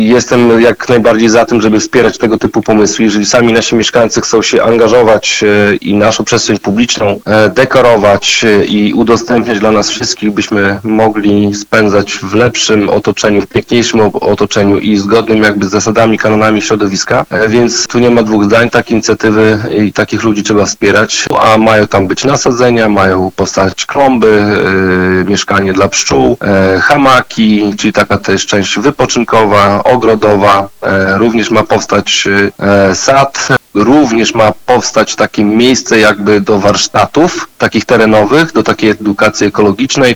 – Po przeanalizowaniu warunków, jakie powinien spełniać teren oraz możliwości, jakie dają gminne dokumenty planistyczne, wyznaczyliśmy działkę obok amfiteatru, blisko jeziora – mówi Radiu 5 Karol Sobczak, burmistrz Olecka.